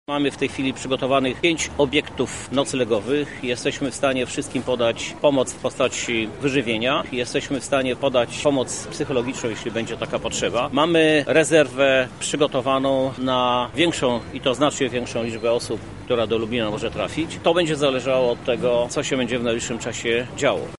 Żadna osoba, która ucieka przed wojną i trafi do Lublina, nie zostanie pozostawiona sama sobie – podkreśla Krzysztof Żuk, prezydent Lublina.